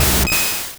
Cri de Kabuto dans Pokémon Rouge et Bleu.